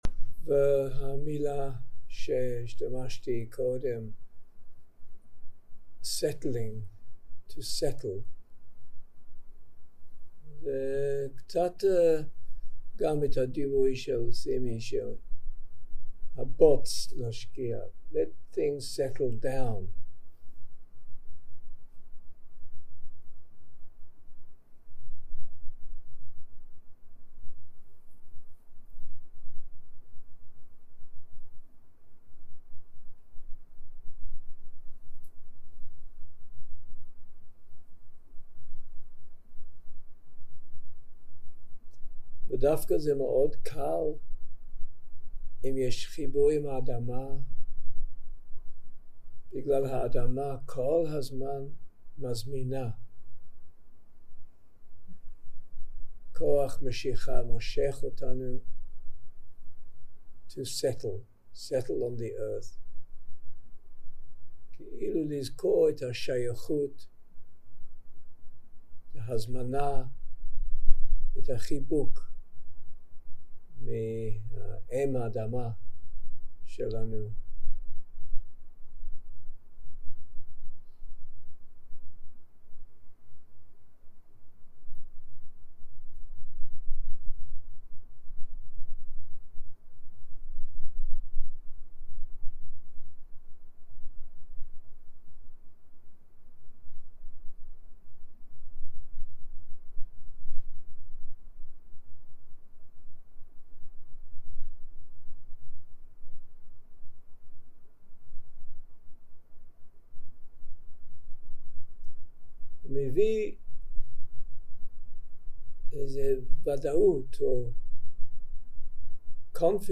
יום 1 – הקלטה 1 – ערב – שיחת דהרמה
Dharma type: Dharma Talks